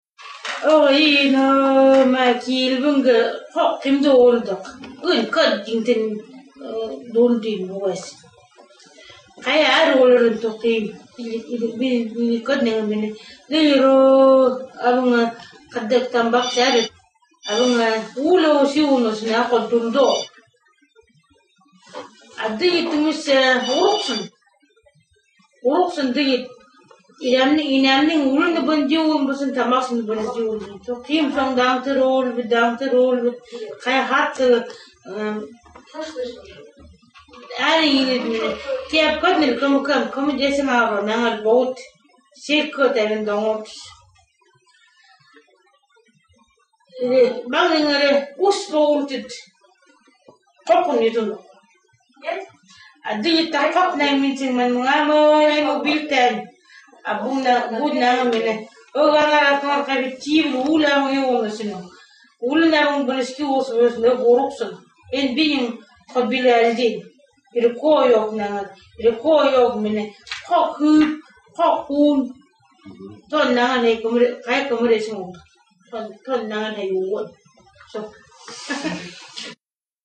Here’s a recording of a folk tale in a mystery language.